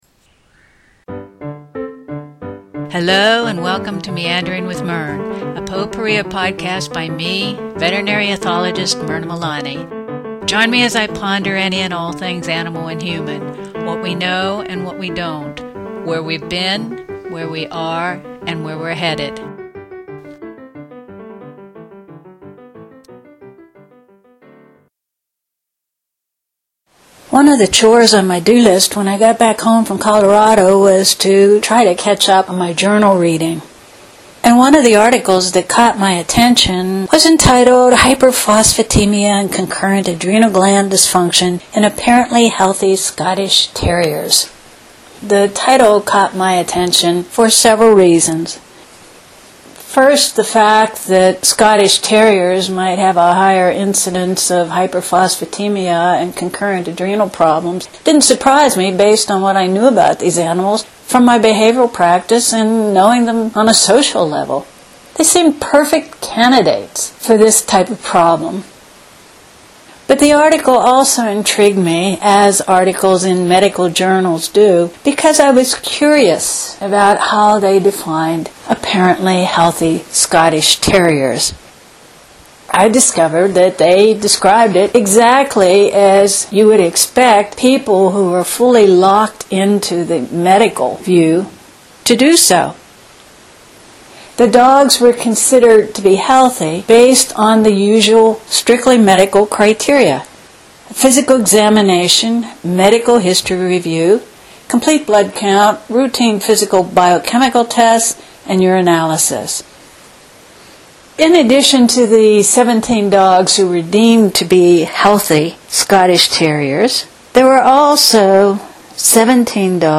This podcast marks my first attempt using the laptop and results were mixed. The quality isn’t as good and some technical difficulties ultimately drove me back to the desktop to finish editing it.